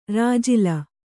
♪ rājila